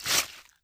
Melee Weapon Attack 25.wav